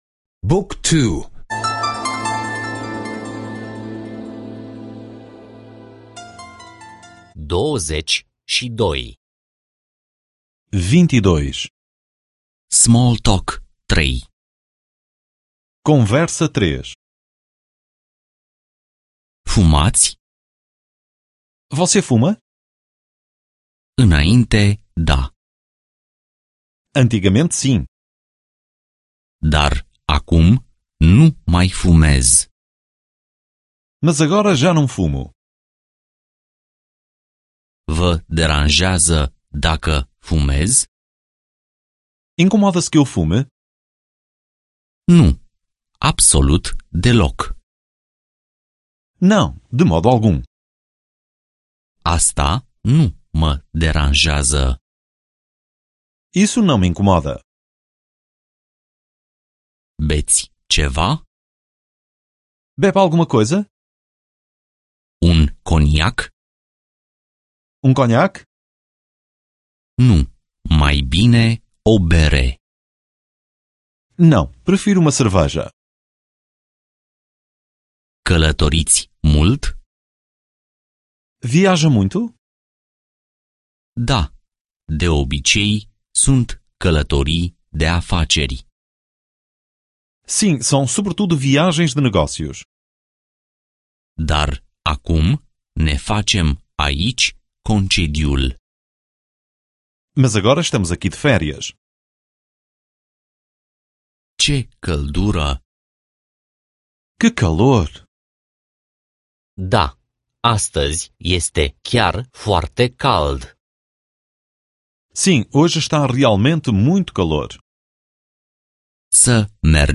Lecții audio de limba portugheză